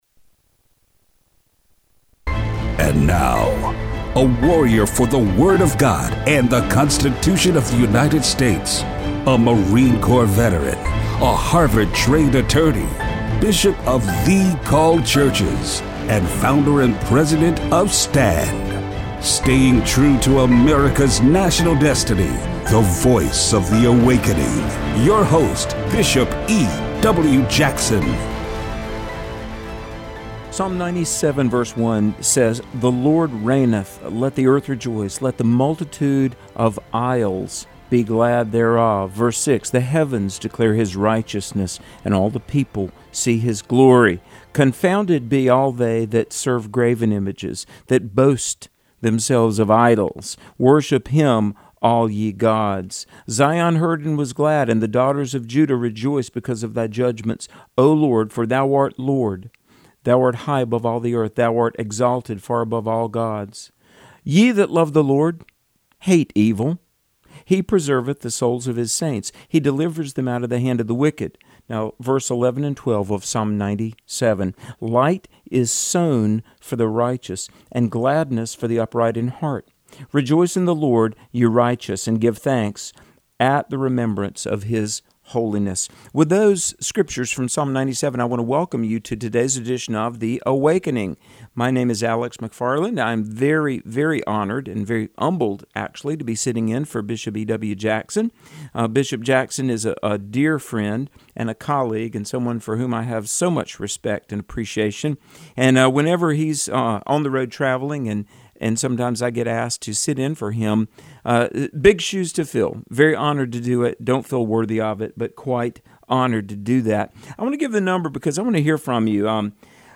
Show Notes Election discussion and listener call-in.